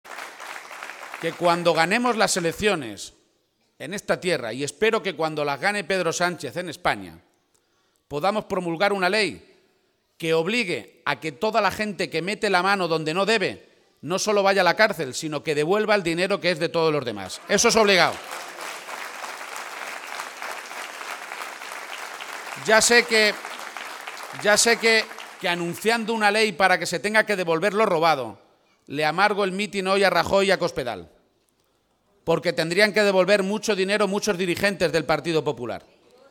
García-Page, que ha participado esta mañana en un acto público con militantes y simpatizantes socialistas en Villatobas (Toledo) ha pedido a Rajoy y Cospedal “que dejen de mentir y de tomar por tontos a los españoles porque cuando hablan de recuperación están hablando solo para uno de cada cuatro ciudadanos de este país, hablan de la recuperación de unos pocos, de aquellos que precisamente especularon y se llenaron los bolsillos a costa de la mayoría de la gente”.